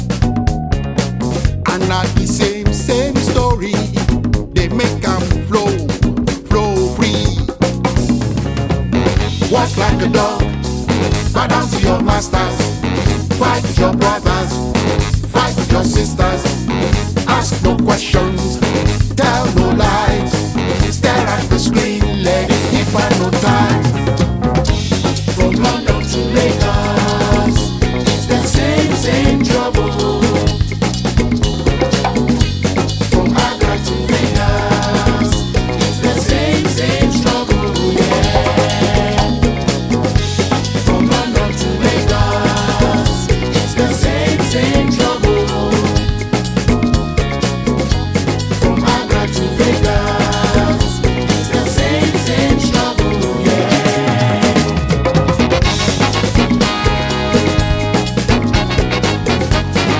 ジャンル(スタイル) SOULFUL HOUSE / AFRO HOUSE